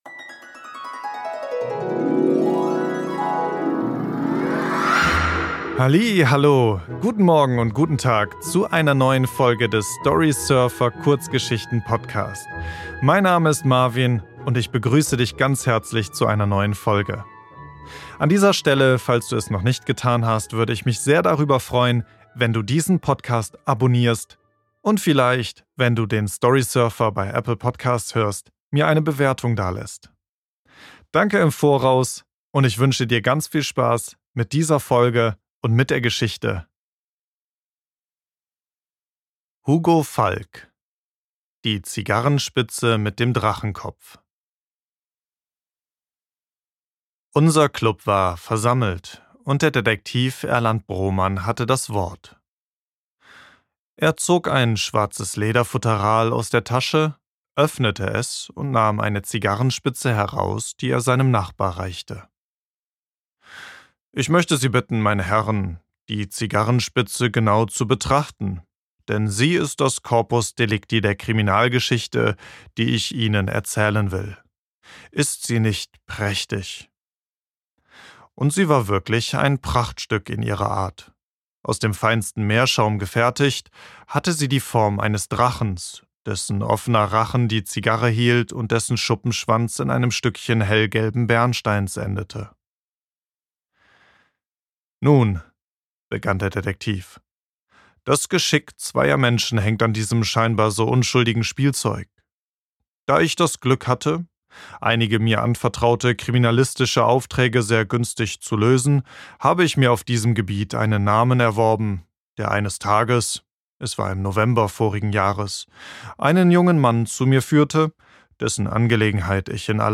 Im Storysurfer-Podcast lese ich in dieser Folge die Geschichte um Detektiv Erland Broman, der anhand einer kunstvollen Zigarrenspitze eine kriminalistische Geschichte erzählt.